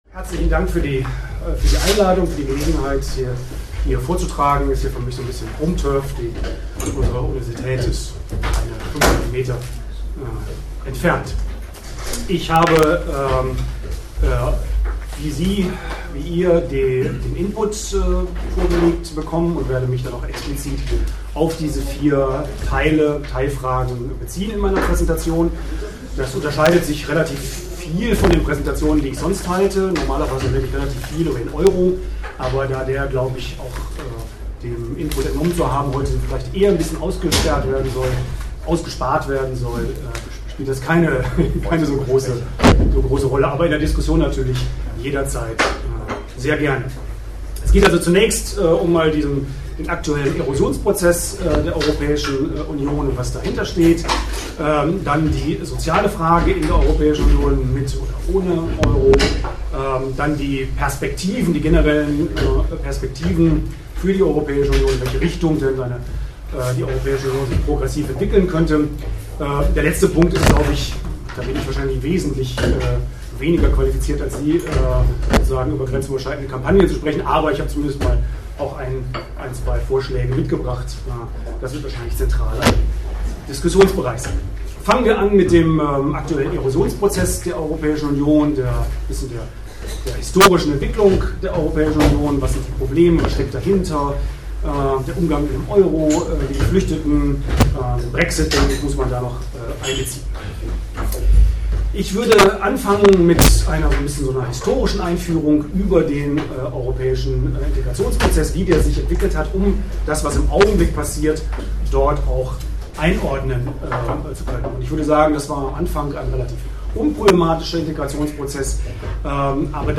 Audio-Aufzeichnung des Inputs (MP3, ca. 44 MB)PowerPoint-Folien dazu (PPT)